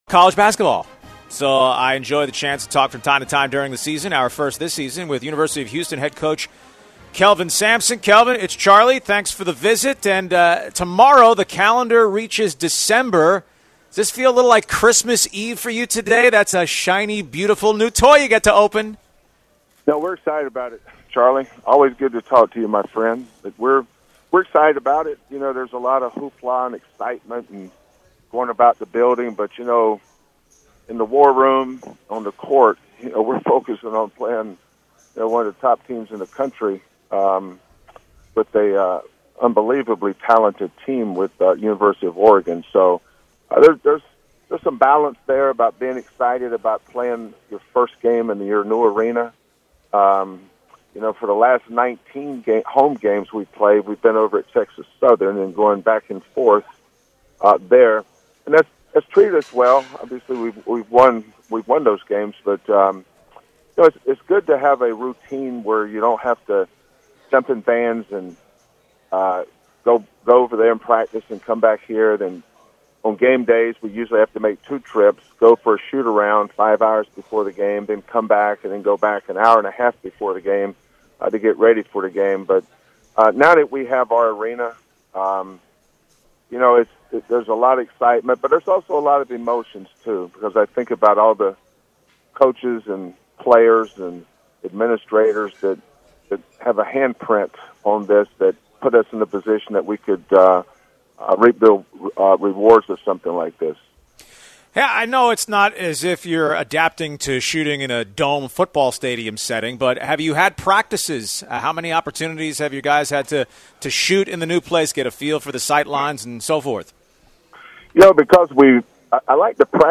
The Kelvin Sampson Interview